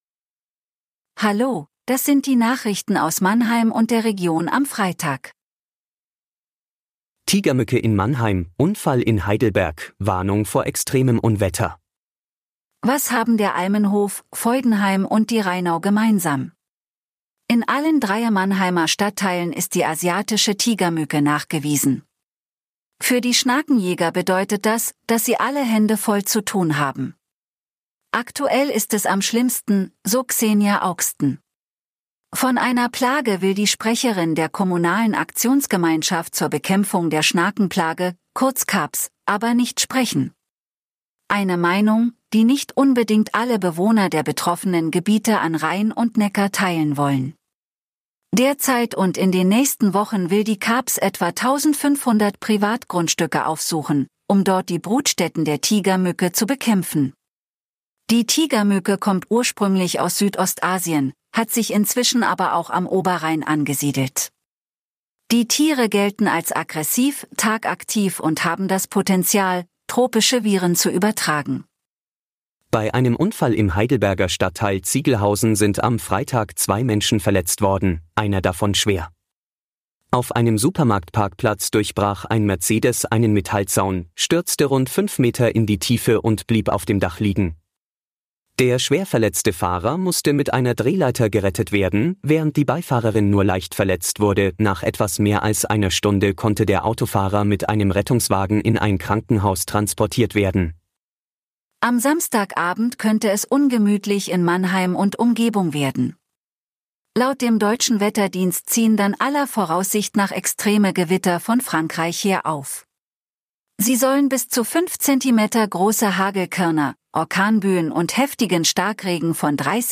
Der Nachrichten-Podcast des MANNHEIMER MORGEN